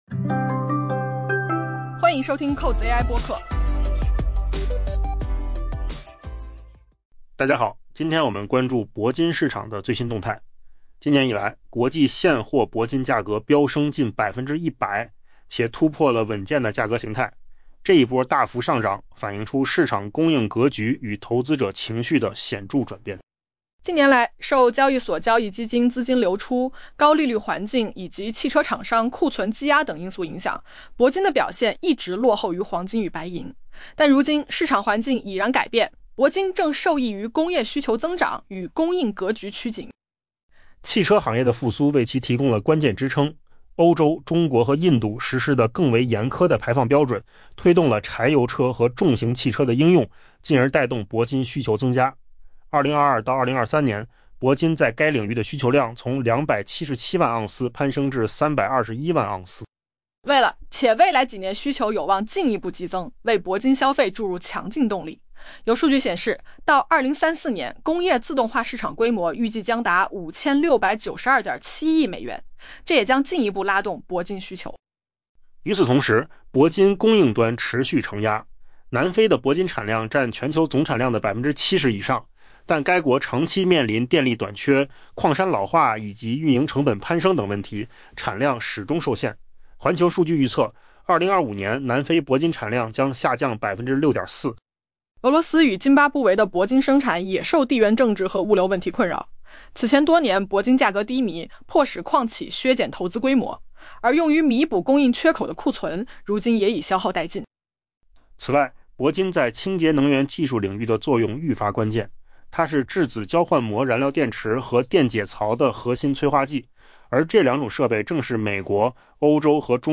AI 播客：换个方式听新闻 下载 mp3 音频由扣子空间生成 今年以来，国际现货铂金价格较飙升近 100%，且突破了稳健的价格形态。